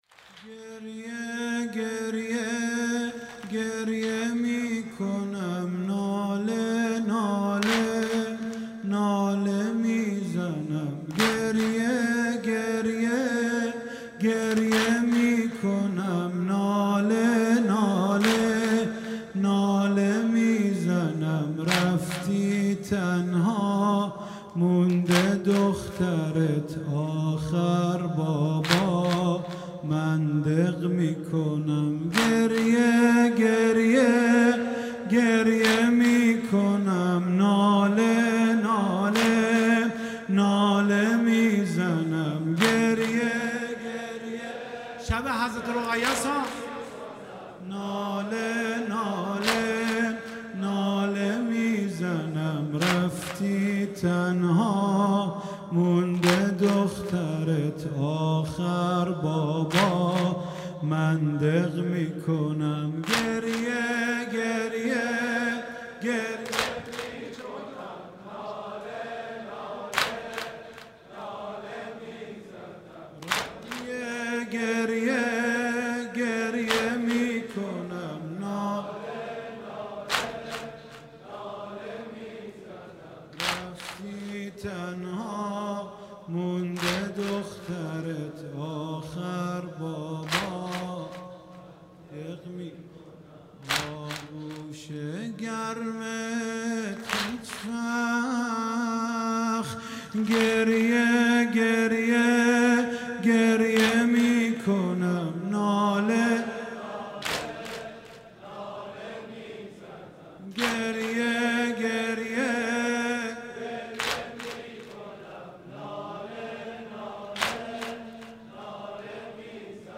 شب سوم محرم 96 - انصار الحسین - واحد - گریه گریه گریه میکنم